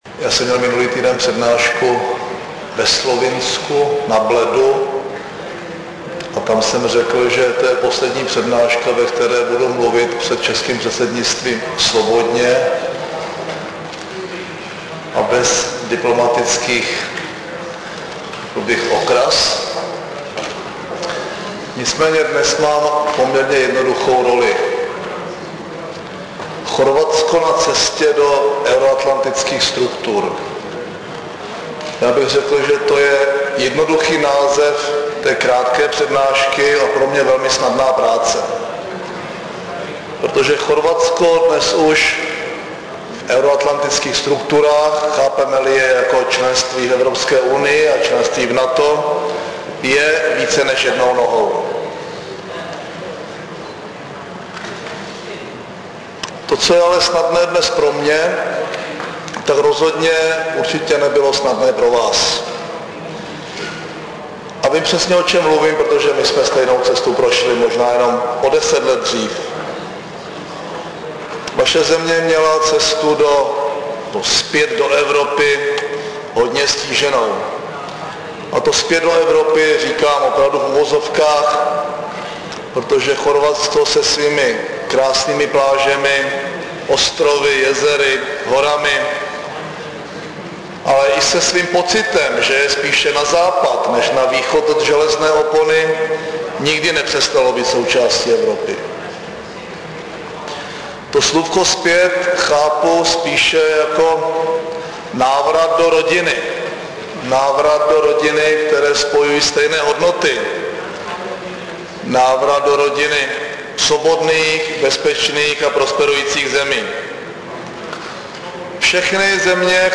Projev premiéra M. Topolánka v Chorvatské republice